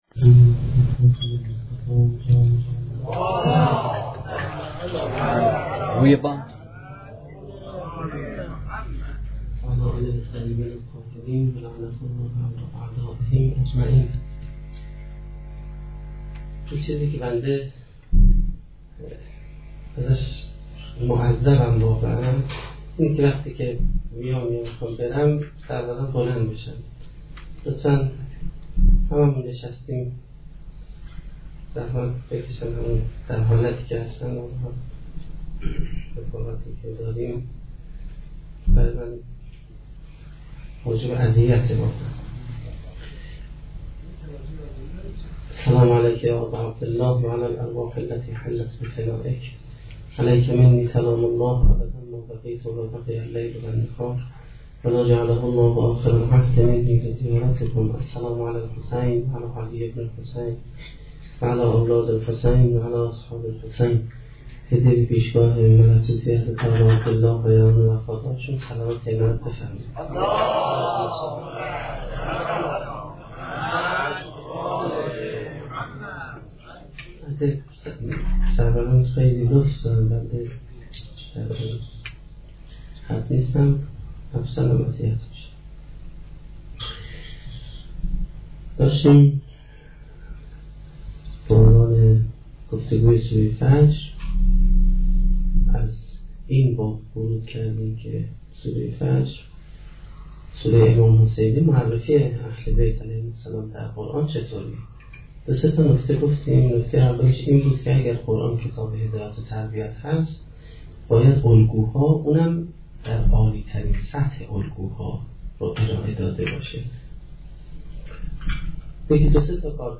سخنرانی پنجمین شب دهه محرم1435-1392